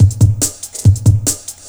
ELECTRO 05-L.wav